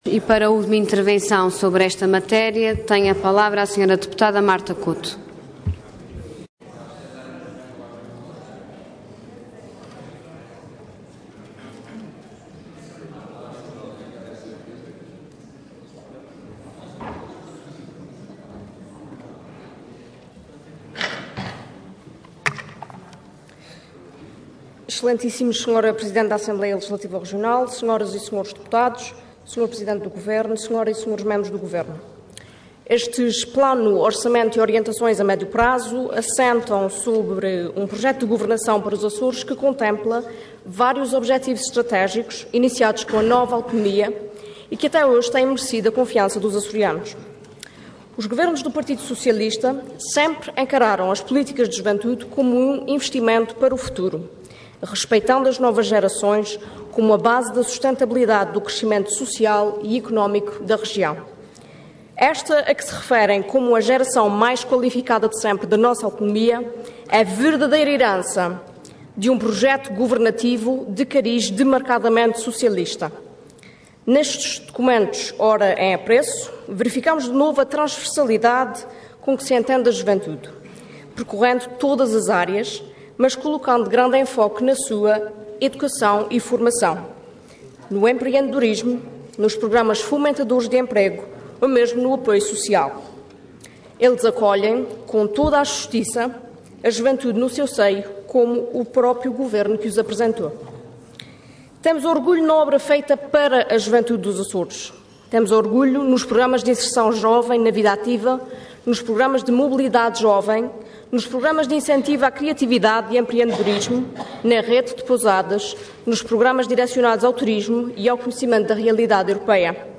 Intervenção Intervenção de Tribuna Orador Marta Couto Cargo Deputada Entidade PS